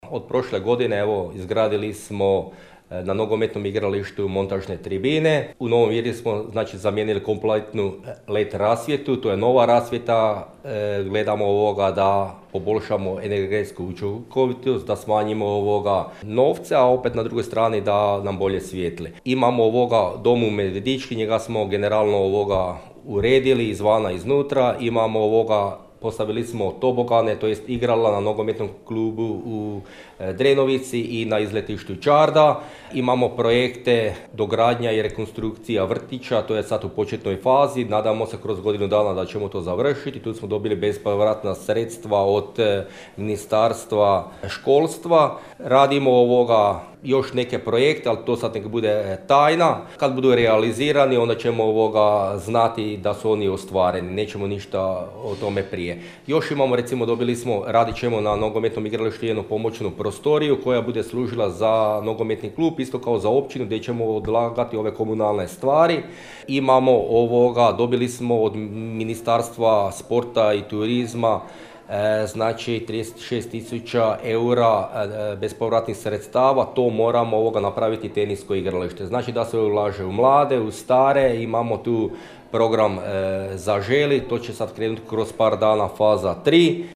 Svečanom sjednicom Općinskog vijeća, Općina Novo Virje je proslavila je Dan Općine i zaštitnika Sv. Josipa Radnika. Načelnik Općine Mirko Remetović, osvrnuo se na ostvarene i planirane projekte te naglasio;